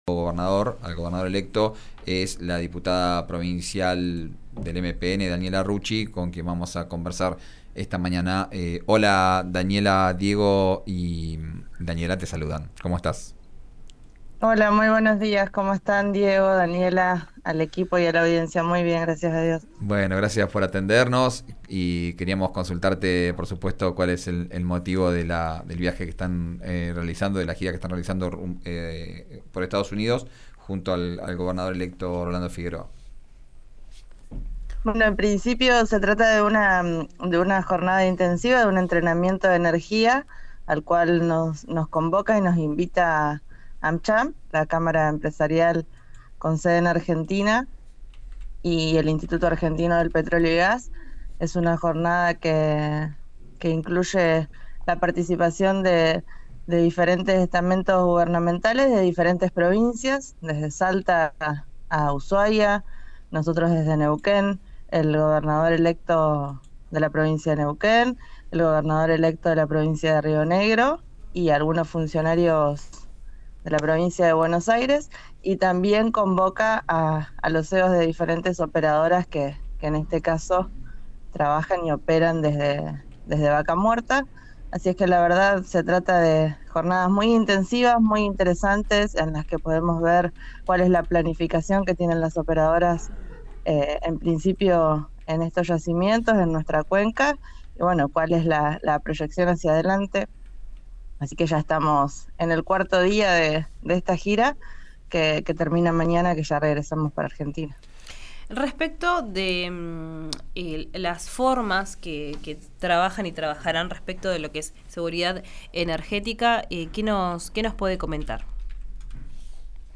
Escuchá a la diputada electa del MPN, Daniela Rucci, en «Vos Al Aire» por RÍO NEGRO RADIO: